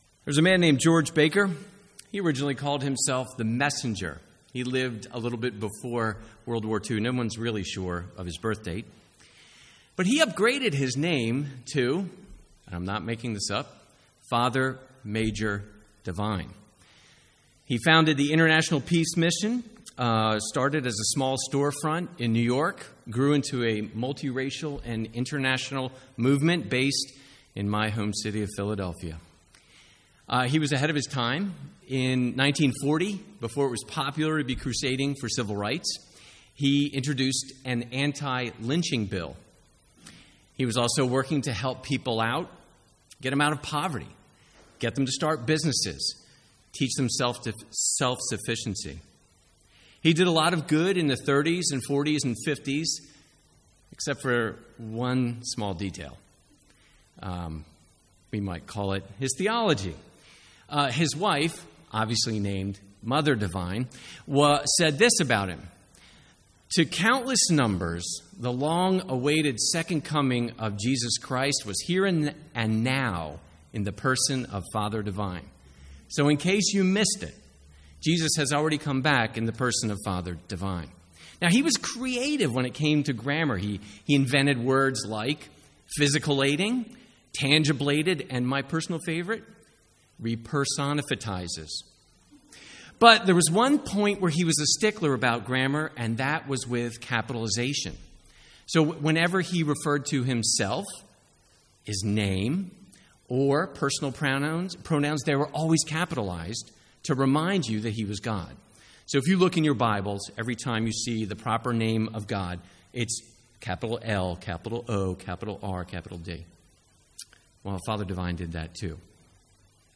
Sermons | St Andrews Free Church
From the Sunday evening series 'Hard Sayings of Jesus'.